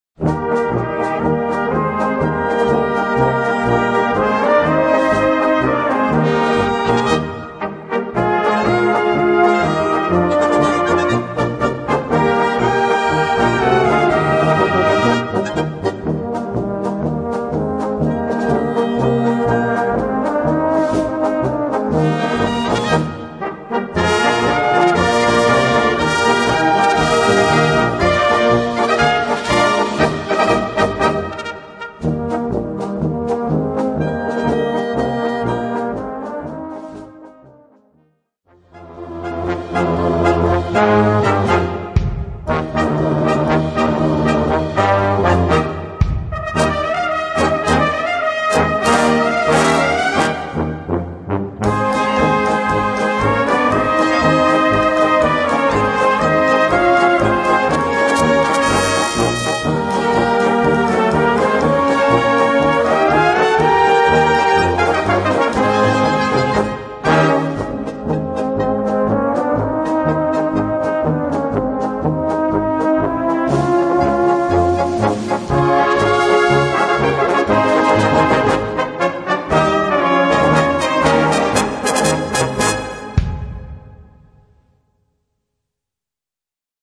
Gattung: Volkstümliche Polka
Besetzung: Blasorchester